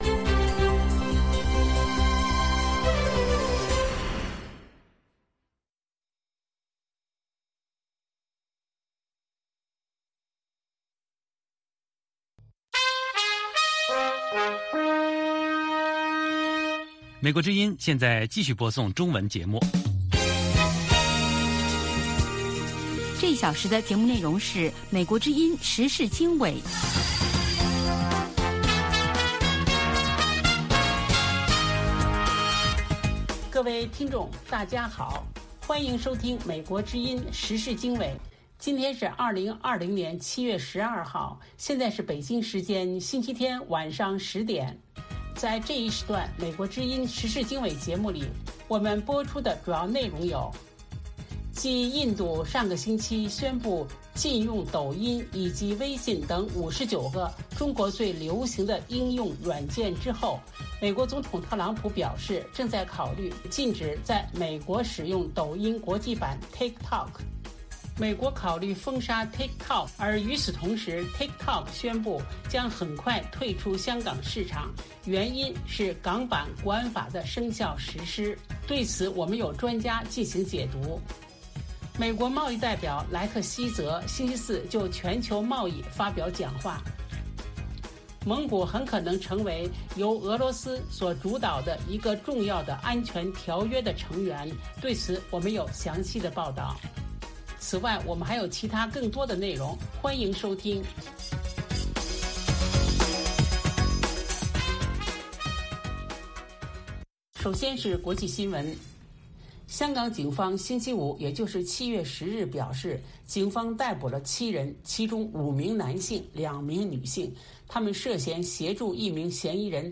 美国之音中文广播于北京时间每天晚上10-11点播出《时事经纬》节目。《时事经纬》重点报道美国、世界和中国、香港、台湾的新闻大事，内容包括美国之音驻世界各地记者的报道，其中有中文部记者和特约记者的采访报道，背景报道、世界报章杂志文章介绍以及新闻评论等等。